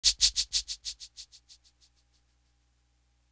Crushed Linen Shaker.wav